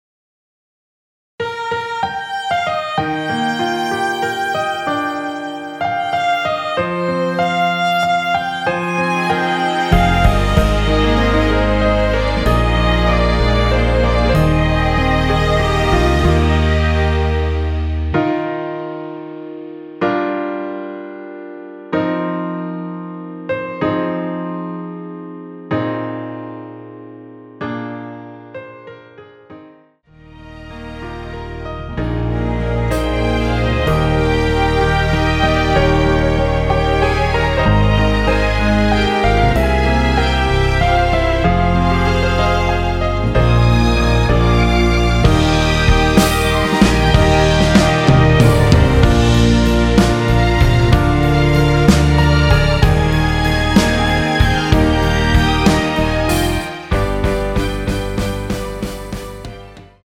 원키에서(+4)올린 1절후 후렴으로 진행되는 MR입니다.
Eb
앞부분30초, 뒷부분30초씩 편집해서 올려 드리고 있습니다.
중간에 음이 끈어지고 다시 나오는 이유는